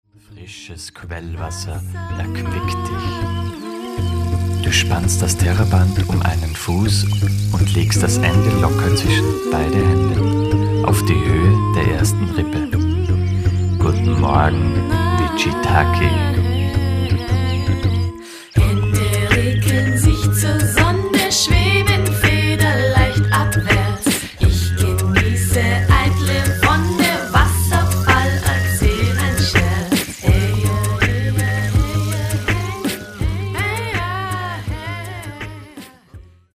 vocals, guitar, percussion, ocarina
Recorded at: Kinderzimmer Studios